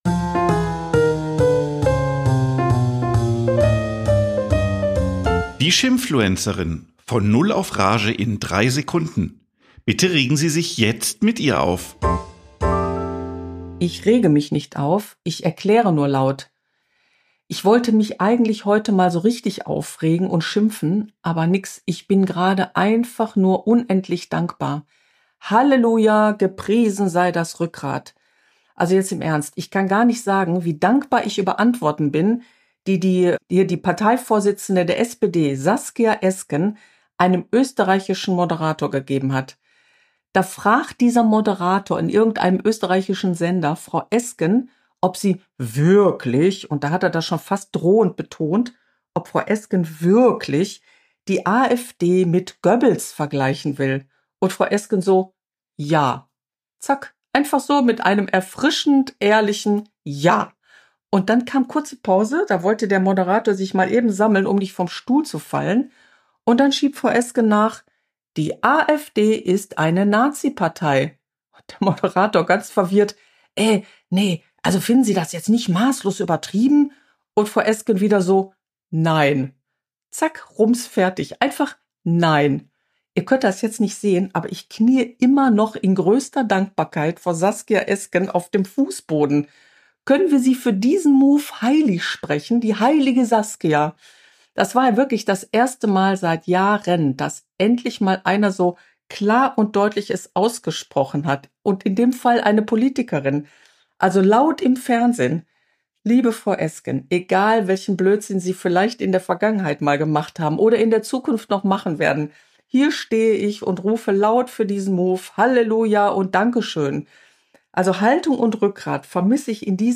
Eine Frau regt sich auf